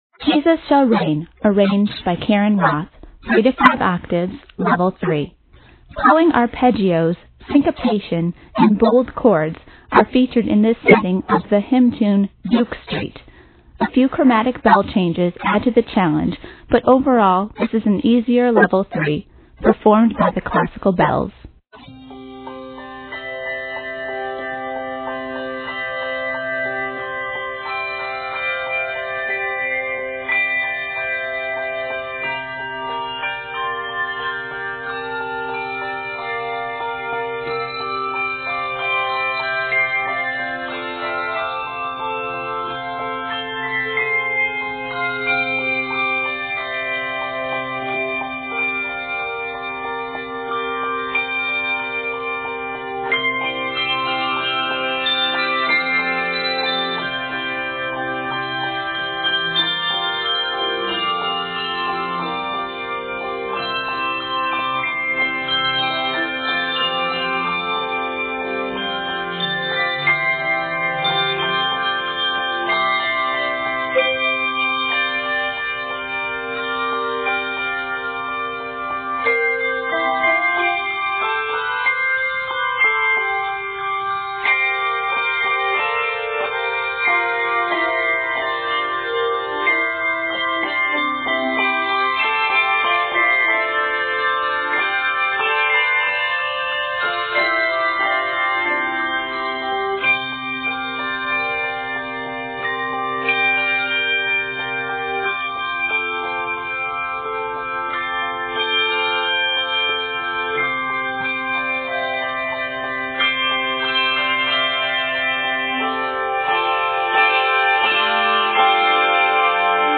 arranged in C Major